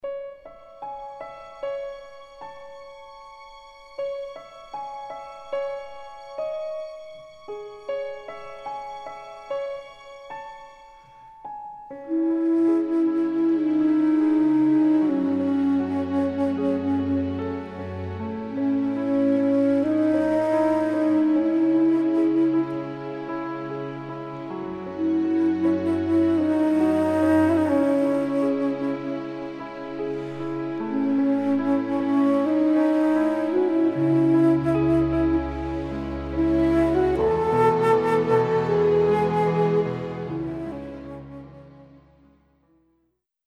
ORIGINAL FILM SOUNDTRACK